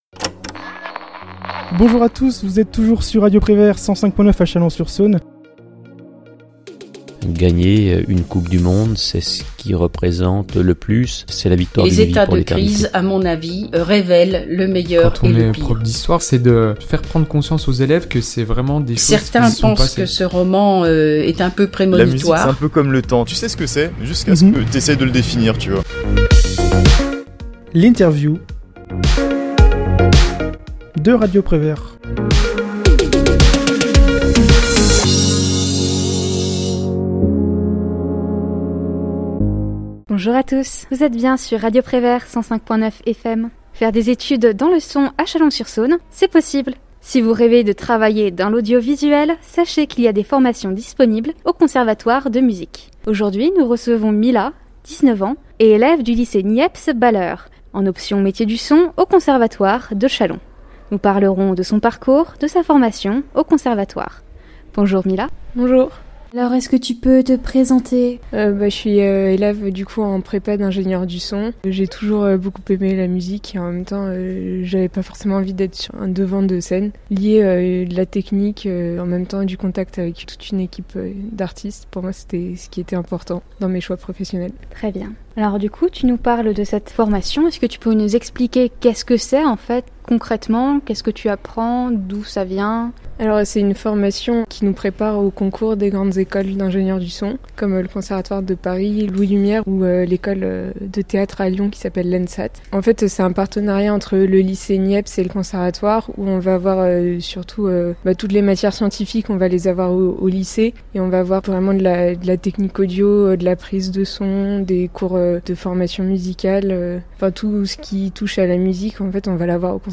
L'Interview de Radio Prévert / Émissions occasionnelles Podcasts